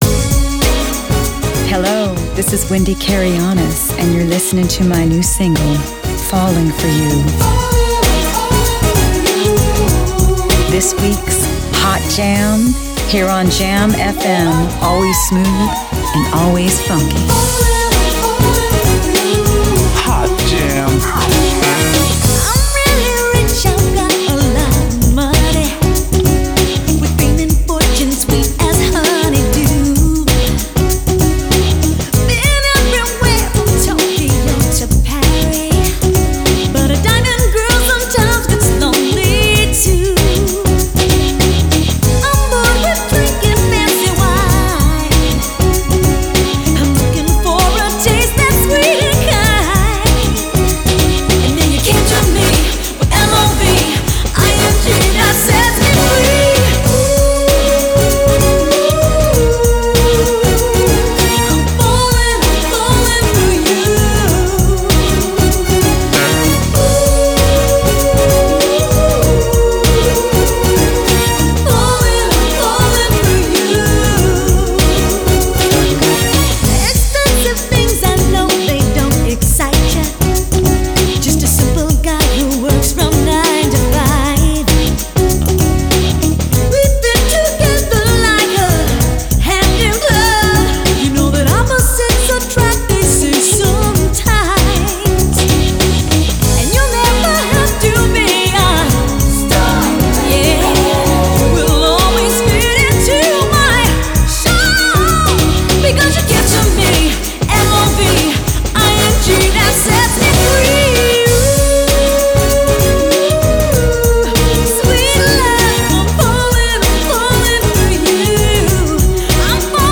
een veelzijdige pop/jazz-zangeres